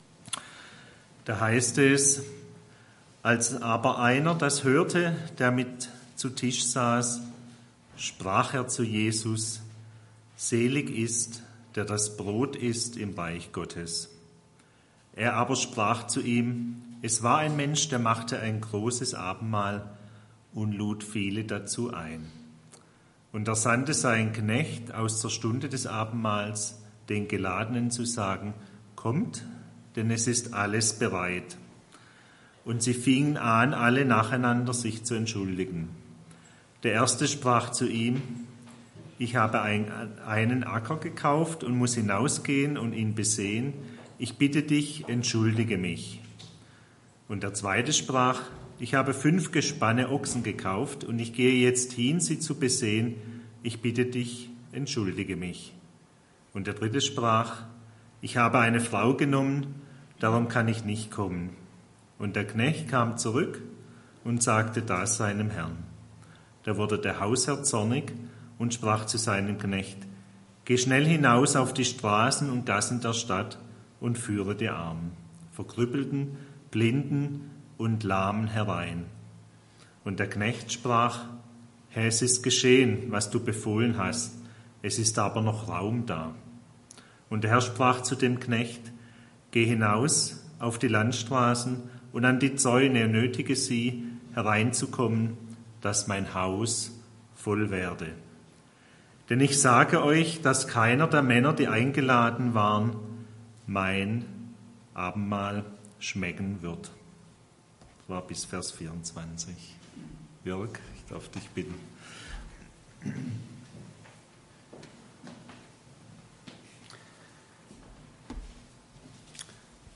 Predigt vom 29.